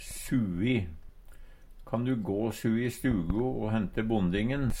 sui - Numedalsmål (en-US)